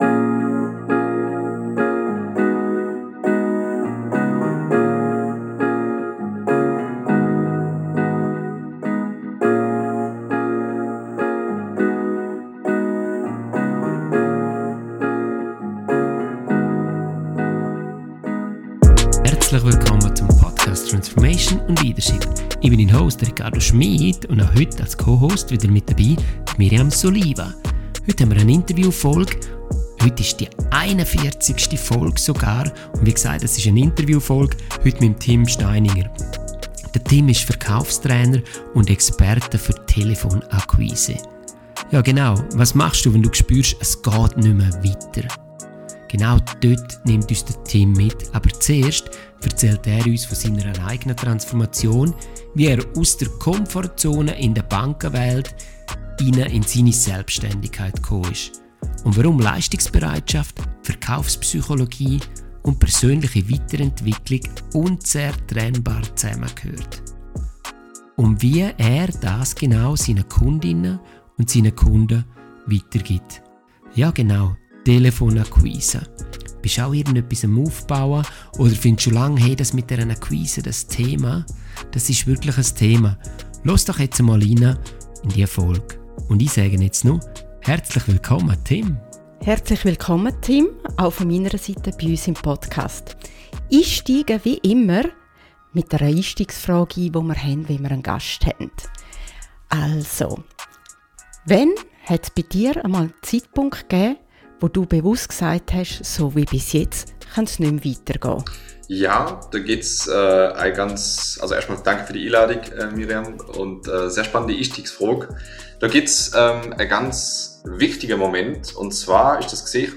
Vom Bankberater zum Verkaufstrainer (Schweizerdeutsch)
Ein Gespräch.